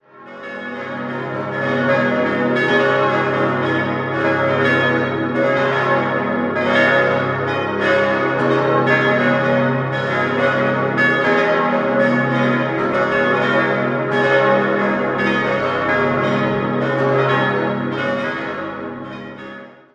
Der imposante Bau der Martinskirche entstand in den Jahren 1908 bis 1910 nach den Plänen des Architekten August Hardegger. 6-stimmiges Geläut: as°-des'-es'-f'-as'-b' Die Glocken wurden im Jahr 1909 von der Gießerei Rüetschi in Aarau gegossen.